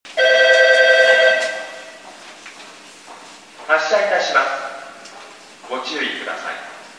駅放送
発車 小音。少し響く。 音量が小さい時が多いです。また接近放送の流れるタイミングが遅めです。